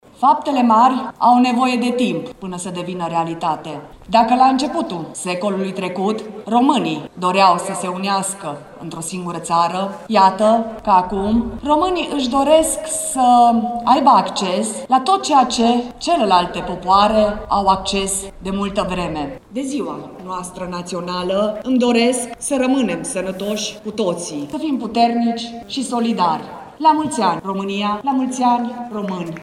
Ziua Națională, sărbătorită la Tg. Mureș
Prefectul Județului Mureș, Mara Togănel, a vorbit despre așteptările românilor din aceste vremuri: